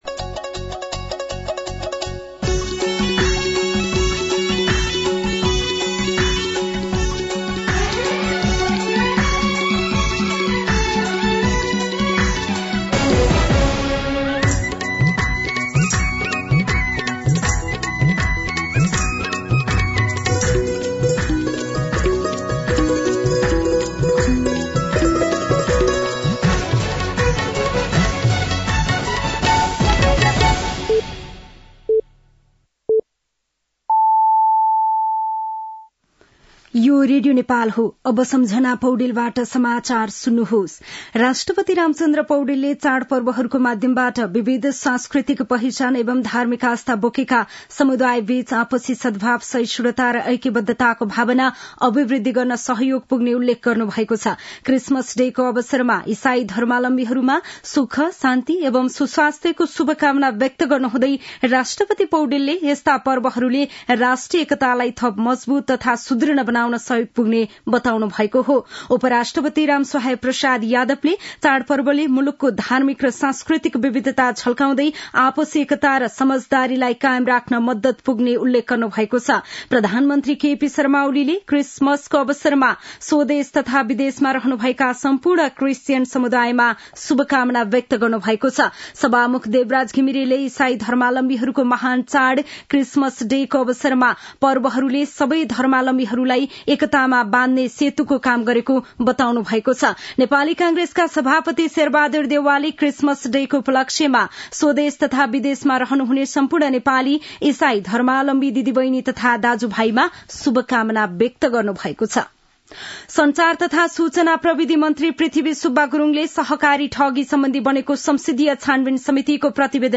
दिउँसो ४ बजेको नेपाली समाचार : ११ पुष , २०८१
4-pm-nepali-news-1-7.mp3